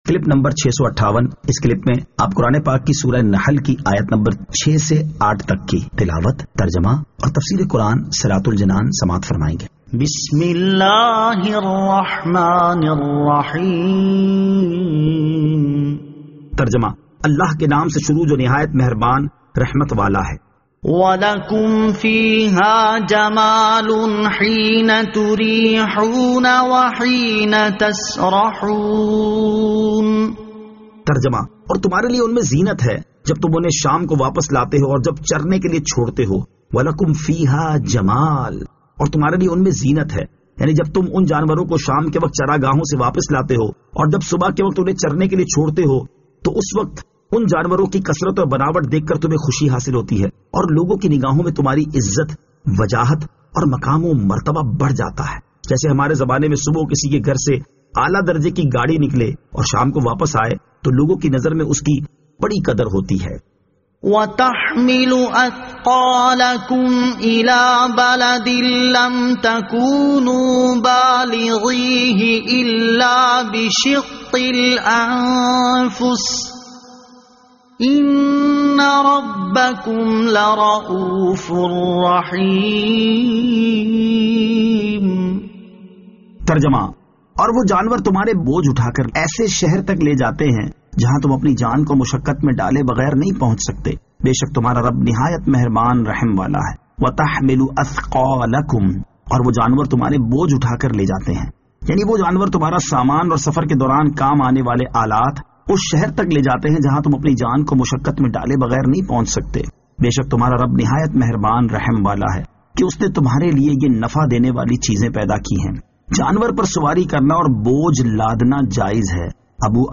Surah An-Nahl Ayat 06 To 08 Tilawat , Tarjama , Tafseer